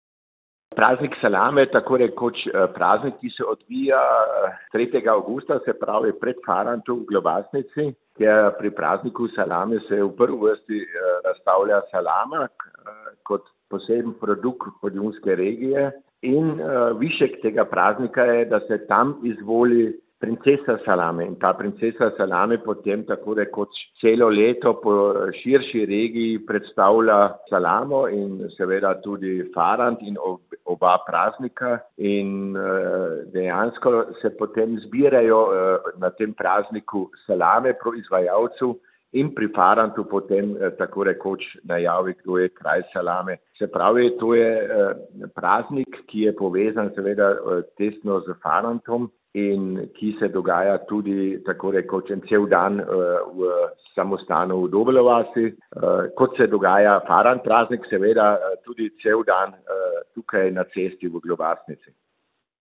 Tradicionalni dogodek se bo začel z bogoslužjem, svoj vrhunec pa dosegel s pokušnjo Podjunskih salam, za katere bo najboljši proizvajalec kasneje na Farantu prejel krono. Župan Občine Globasnica Bernhard Sadovnik: